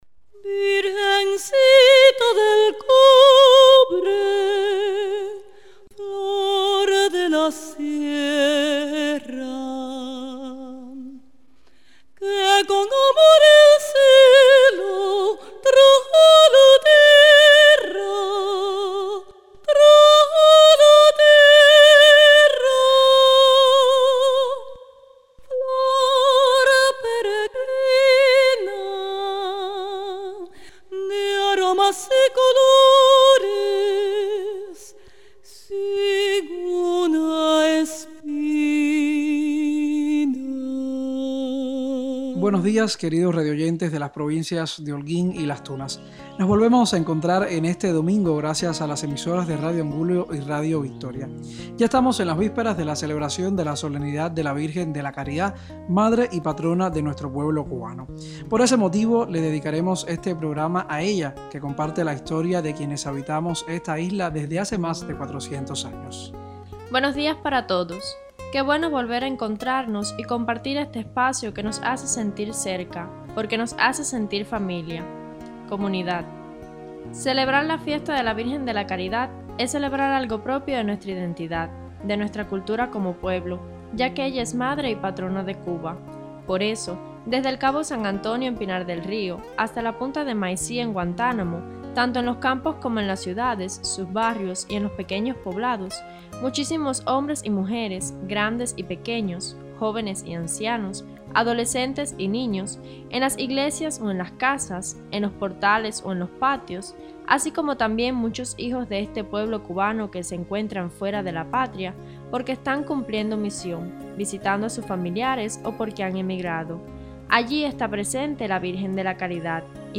HOLGUÍN, Cuba.- Los cubanos en las provincias de Holguín y Las Tunas han escuchado en la mañana del domingo 6 de septiembre una lección de historia cubana y de devoción patria a través de las ondas radiales de las emisoras Radio Angulo y Radio Victoria.
Con motivo de la preparación a la fiesta de la Virgen de la Caridad, Patrona de Cuba, el obispo, Mons. Emilio Aranguren Echeverría, se ha dirigido al pueblo recordando los hitos de la presencia de la Madre de Dios en la historia de Cuba y comparando la acción de la Iglesia en medio del pueblo a la que tuvo la Madre de Jesús en las bodas de Caná, según la narración del evangelio de San Juan (2. 1-11) proclamada durante el programa radial.
Han recibido motivaciones por varios locutores y han escuchado canciones, para muchos conocidas, por ser parte de las celebraciones en los templos.